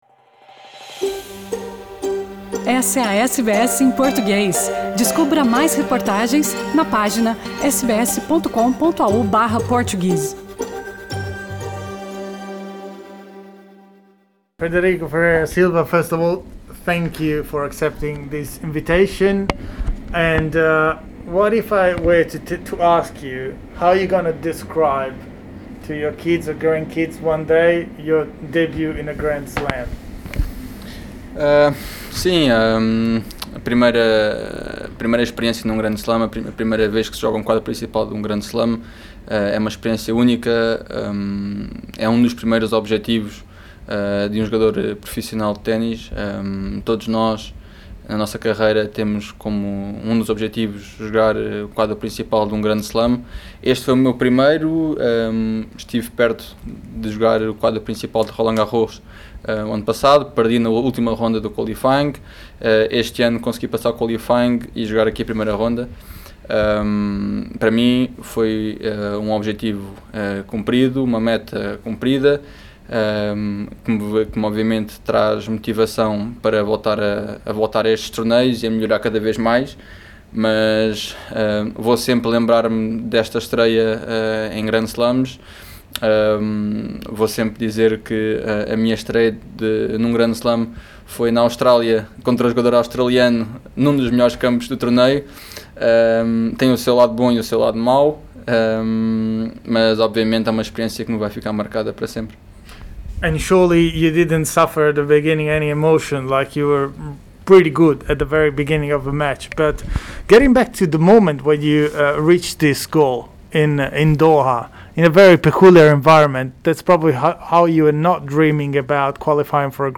Confira a entrevista completa clicando no ícone acima. As perguntas foram feitas em inglês e respondidas em português pelo tenista português.